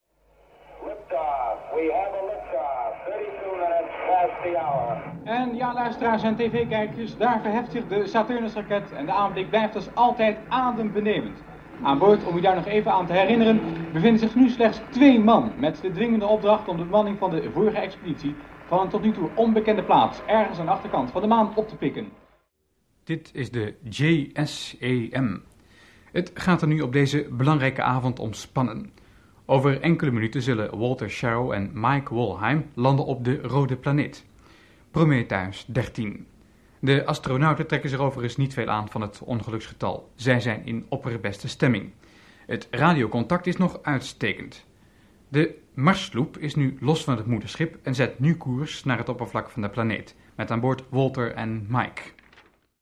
– Apollo XXI (De gesluierde planeet) – journalist
– Prometheus XIII – tv-verslaggever / Fluisterende jongen in de kerk / Nieuwslezer